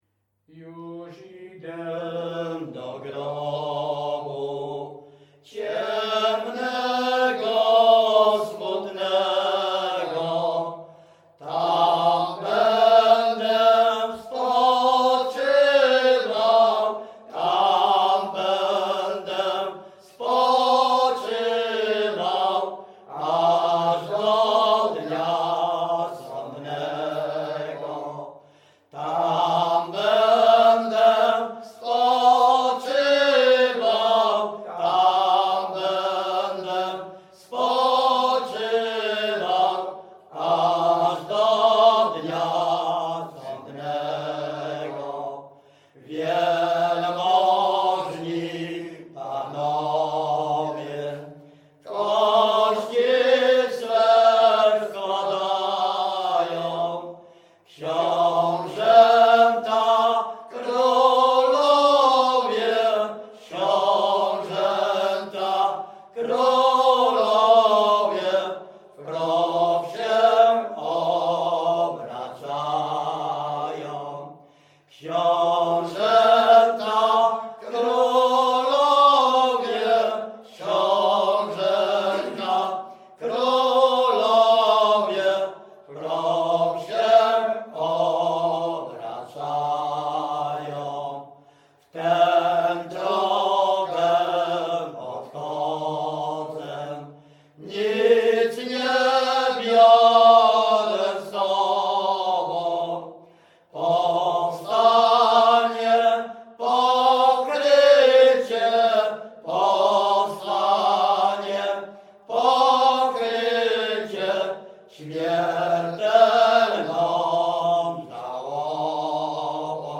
performer Śpiewacy z Ruszkowa Pierwszego
Coverage Wielkopolska, powiat kolski, gmina Kościelec, wieś Ruszków Pierwszy
śpiewają ze sobą od młodych lat, mają bardzo bogaty repertuar pieśni (zwłaszcza religijnych) w miejscowych wariantach melodycznych genre Pogrzebowa
Ruszków Tags pogrzebowe nabożne katolickie do grobu New Tags I agree with terms of use and I accept to free my contribution under the licence CC BY-SA .